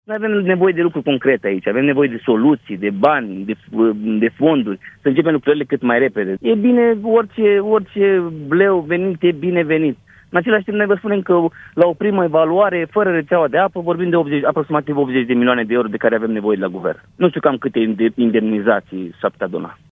Președintele Consiliului Județean Suceava, Gheorghe Șoldan, spune la Europa FM că pagubele evaluate de autorități se adună la aproximativ 80 de milioane de euro